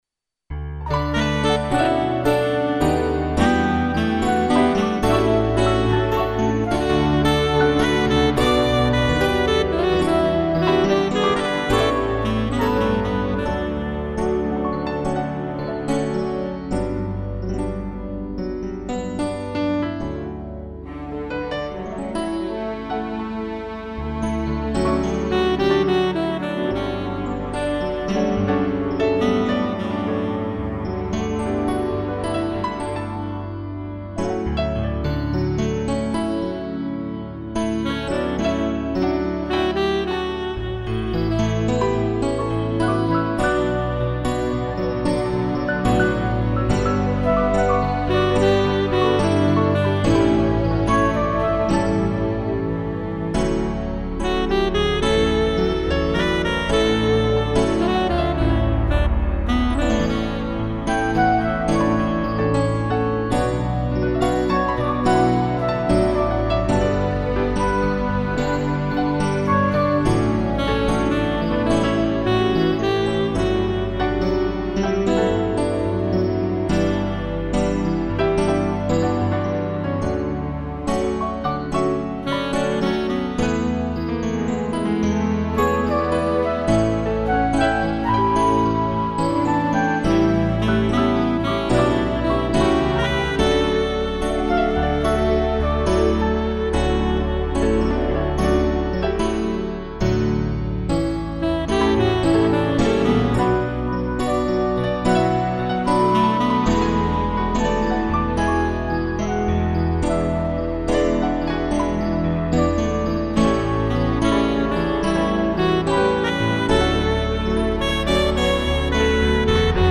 2 pianos, flauta, sax e cello
instrumental